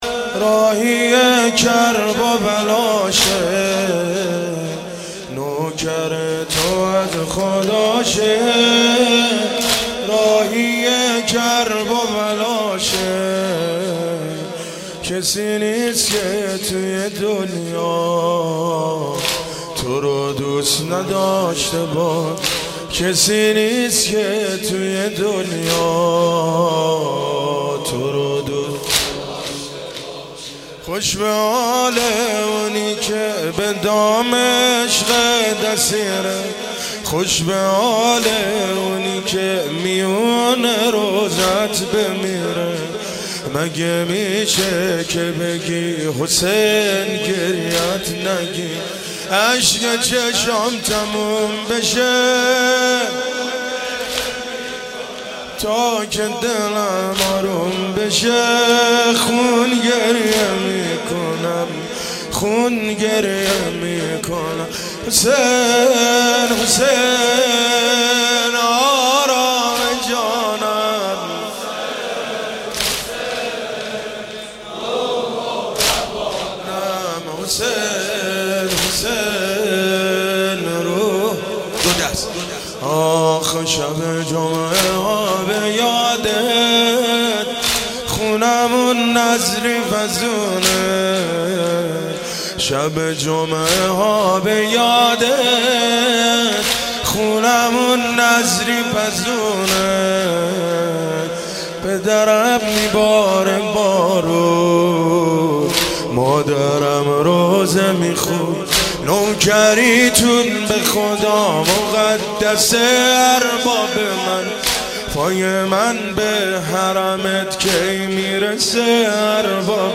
شام وفات حضرت ام البنین(س) در هیئت الرضا(ع)
روضه ، زمینه ، شور
سینه زنی واحد ، تک ، شور